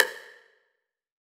6RIM SHOT.wav